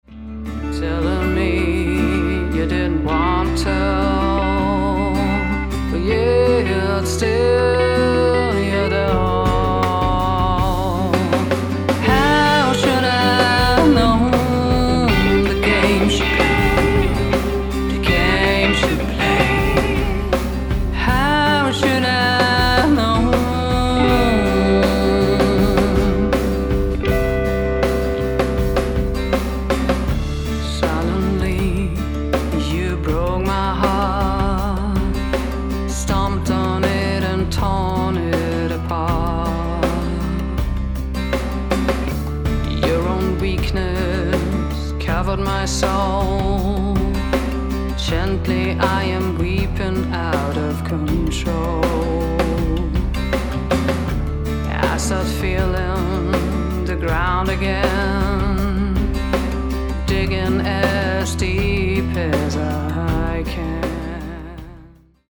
... is a singer and songwriter from Vienna, Austria.